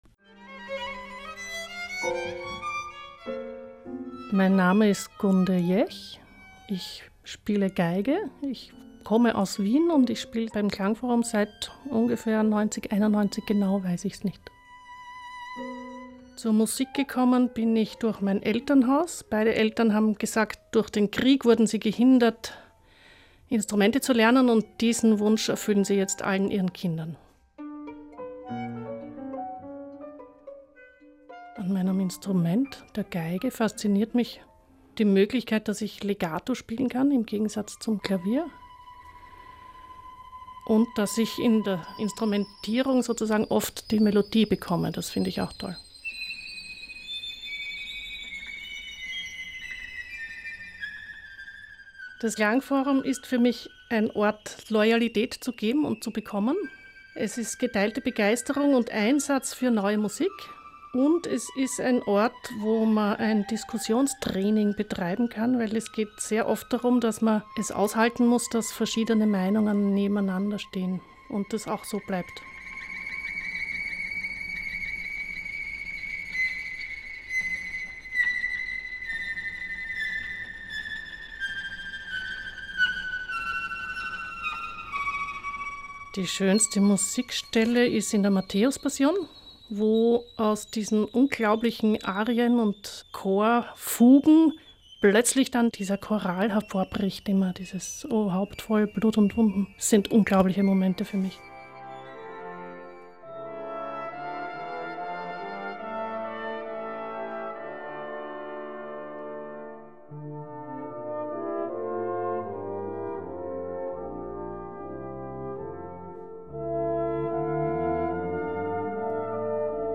Ö1 Audio-Porträt https